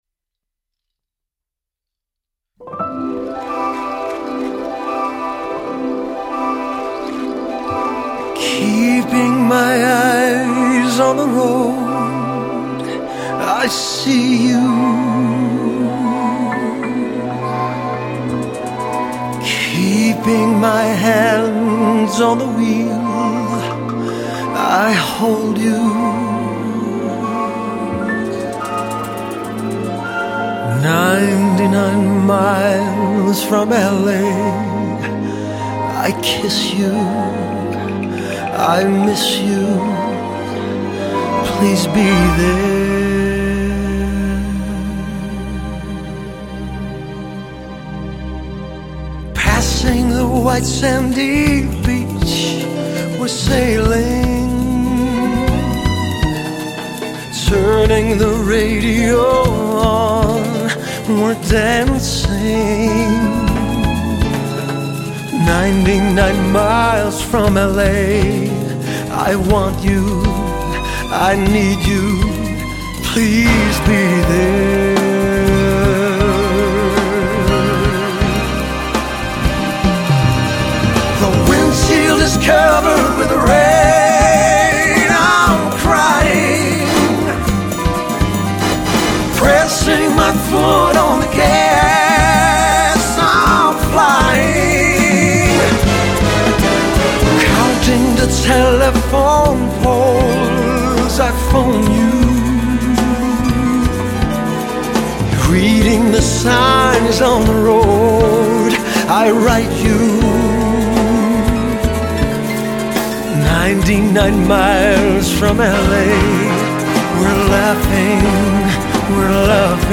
polished, exciting jazz singer